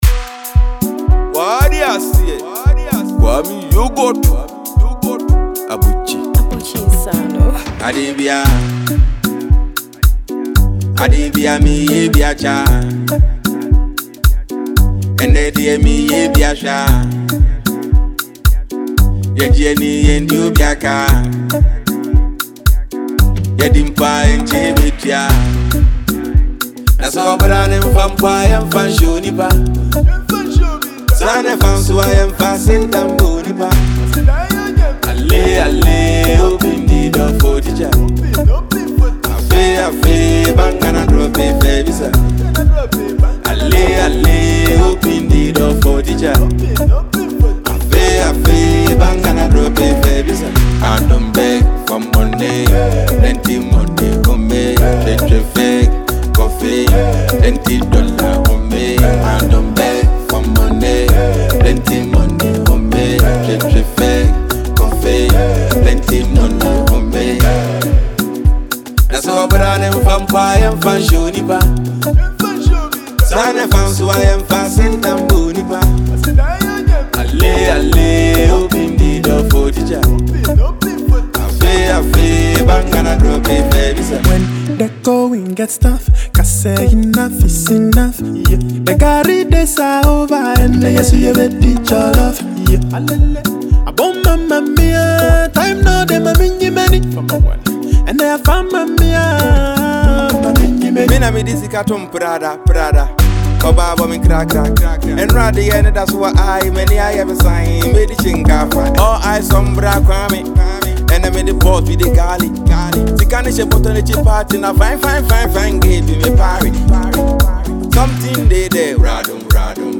• Genre: Hiplife